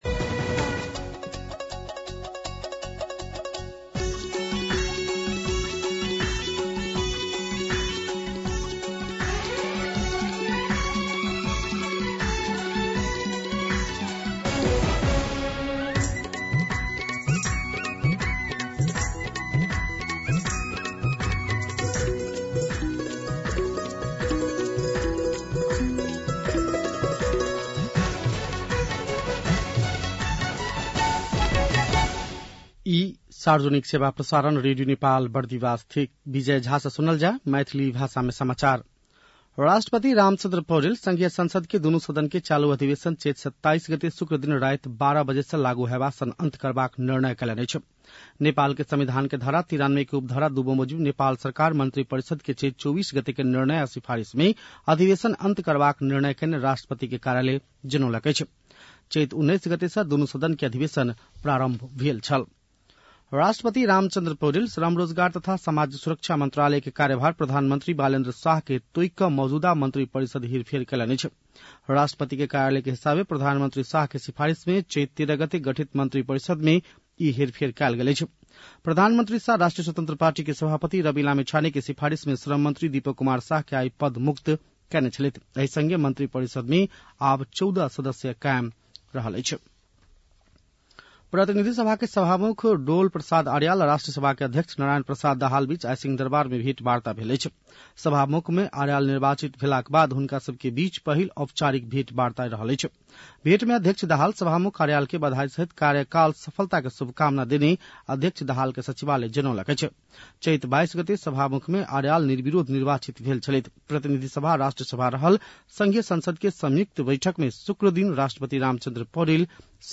मैथिली भाषामा समाचार : २६ चैत , २०८२
6.-pm-maithali-news-1-1.mp3